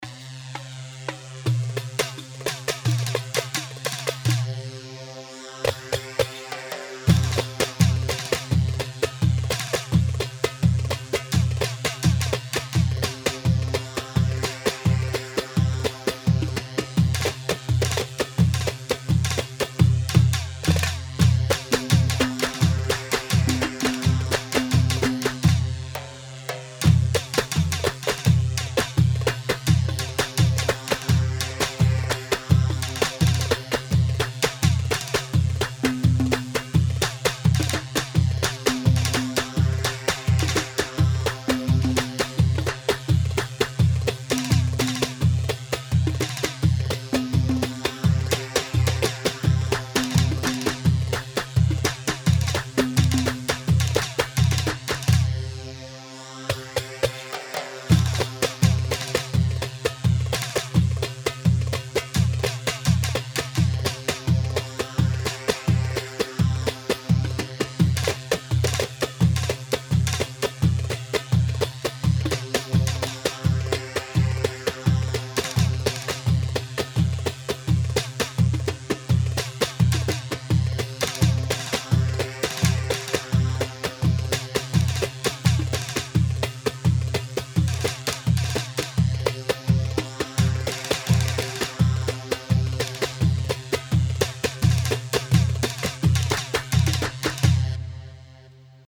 Iraqi
Hewa 4/4 85 هيوا